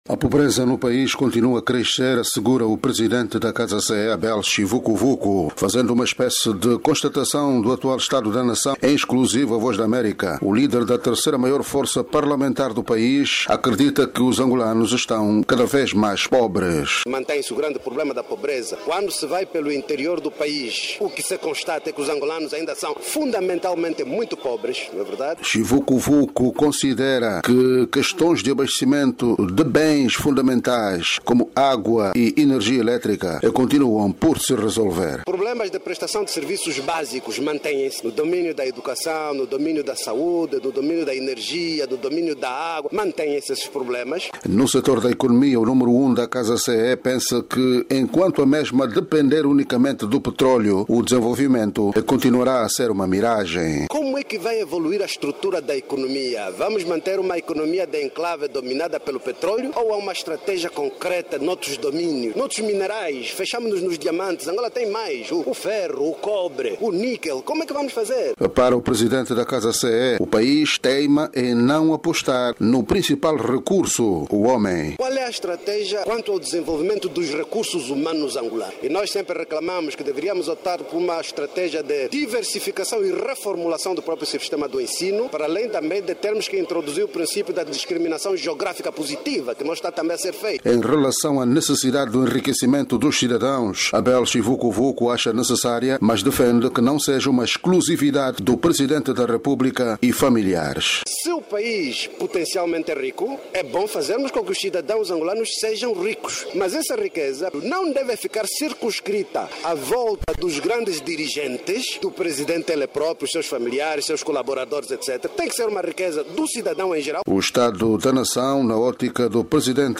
Pobreza no país está a aumentar, diz o dirigente da CASA CE em entrevista á VOA